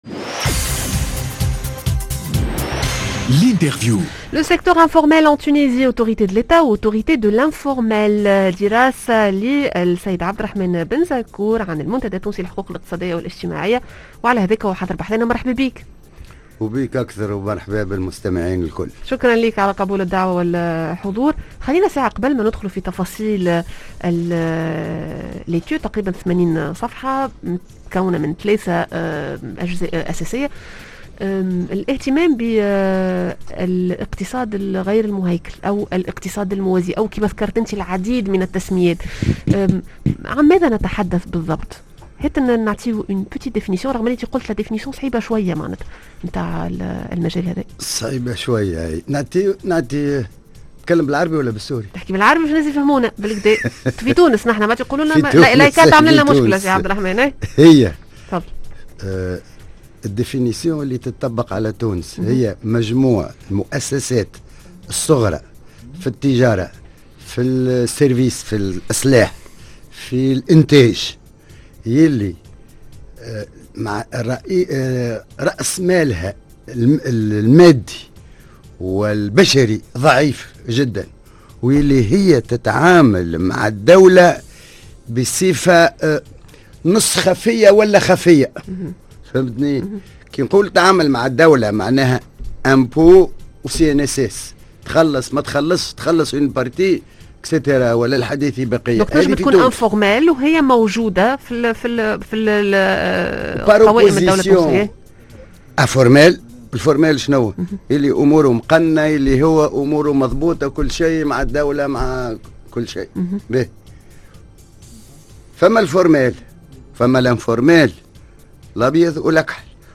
L'interview: القطاع الموازي استشرى و الدولة ويني؟